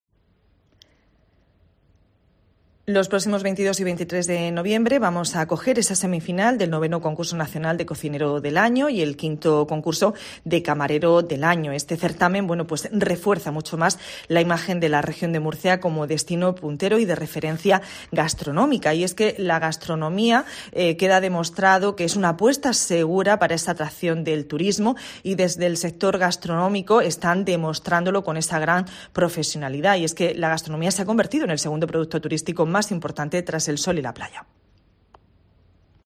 Carmen Conesa, consejera de Cultura, Turismo, Juventud y Deportes